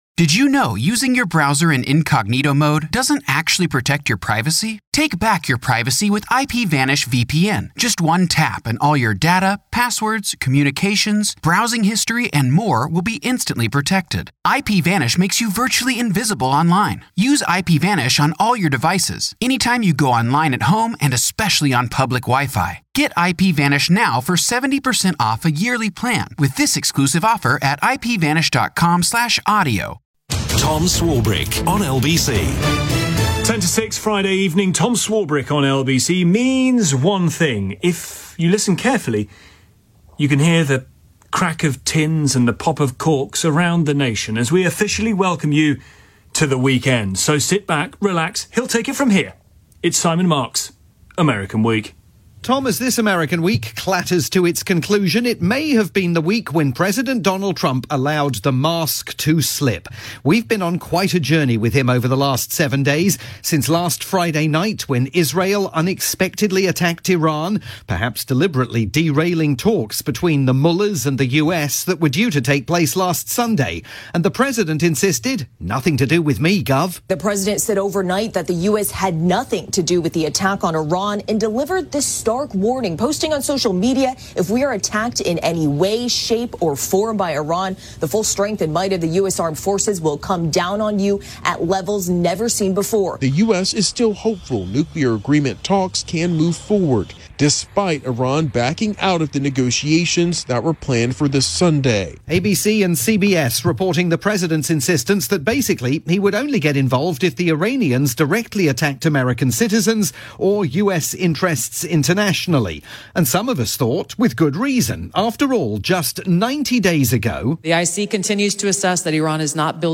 Friday drivetime programme on the UK's LBC. This week: the President holds the world's attention hostage over Iran.